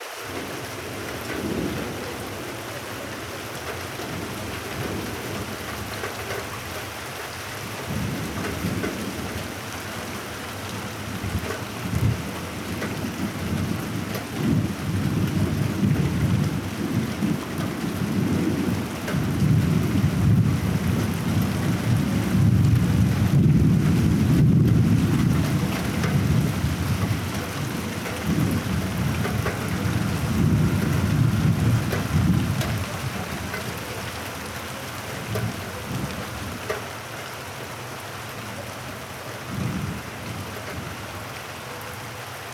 Lluvia en la tormenta
Sonido de la lluvia cayendo en mitad de una tormenta.
Sonidos: Agua
Sonidos: Naturaleza